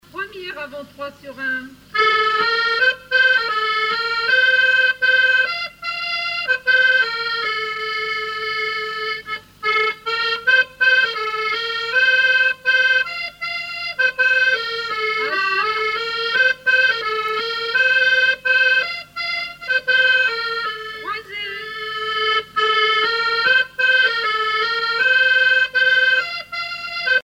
danse : quadrille : avant-trois
Musique du quadrille local
Pièce musicale inédite